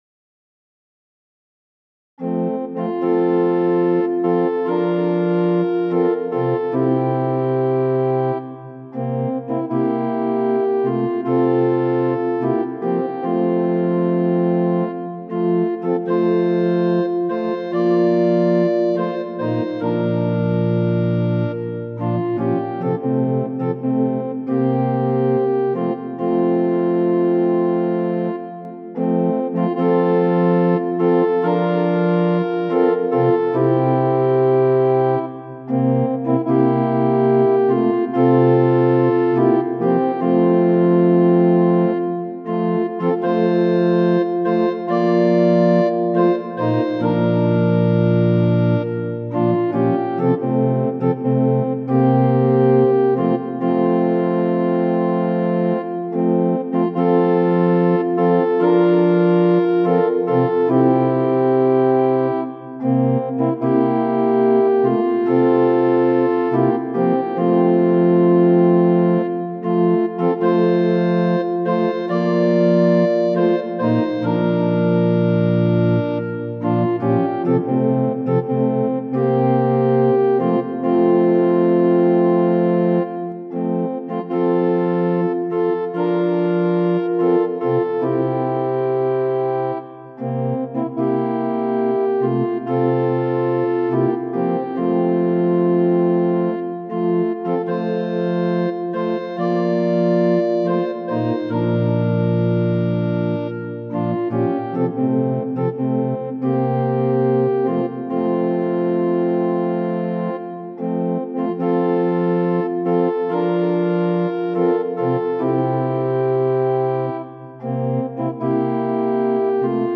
♪賛美用オルガン伴奏音源：
・短い前奏があります
・節により音色、テンポ、和声が変わる場合があります
・間奏は含まれていません
Tonality = F
Pitch = 440
Temperament = Equal